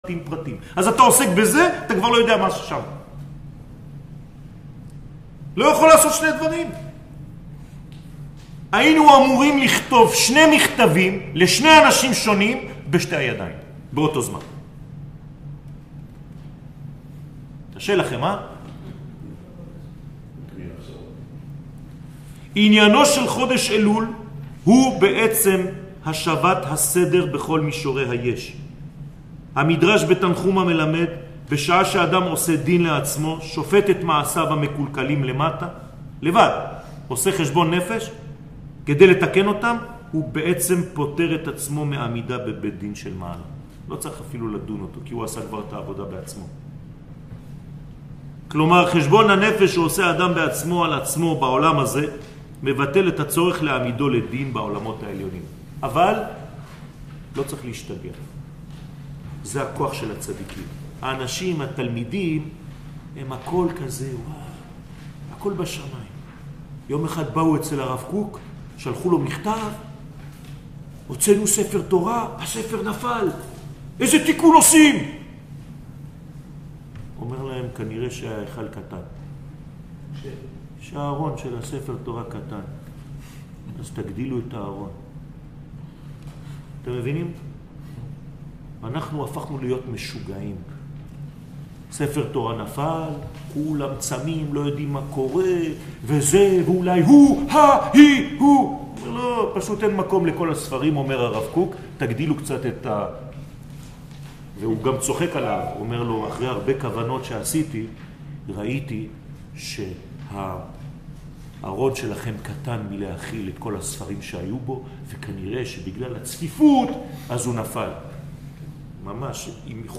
שיעורים